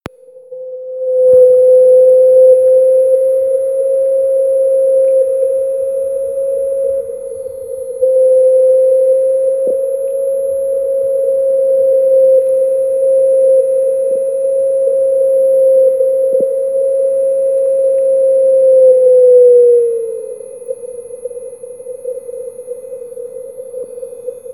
Die Signale an der Antenne R&S HE011 schwankten zwischen S8 und S9+10dB.